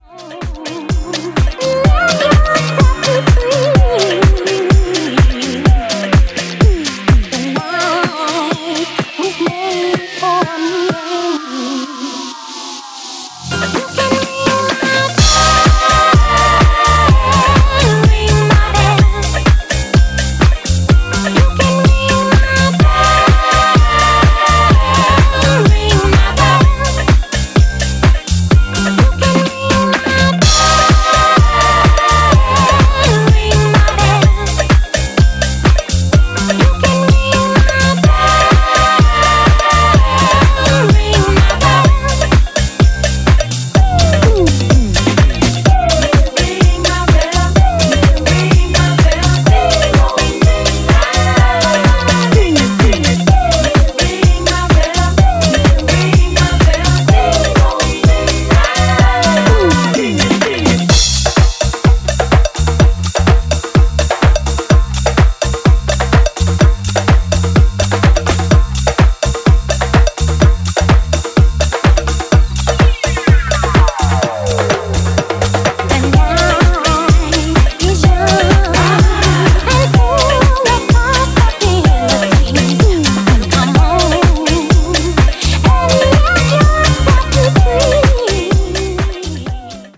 オリジナルのグルーヴを尊重した、何気に行き届いた作りです。
ジャンル(スタイル) DISCO / HOUSE / EDITS